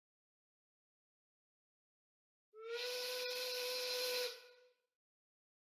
Arquivo:Buzinadefumaca-audio.ogg